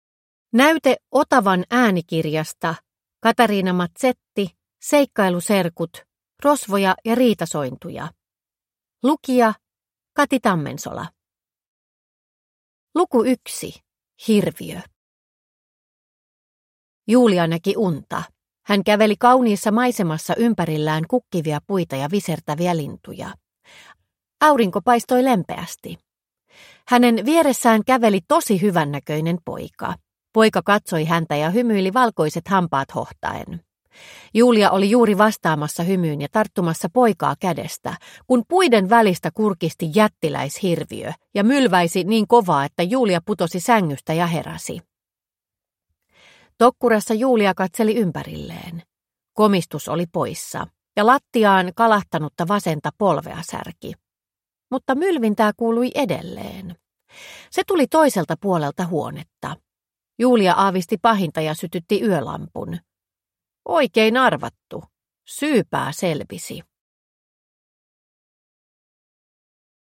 Rosvoja ja riitasointuja (ljudbok) av Katarina Mazetti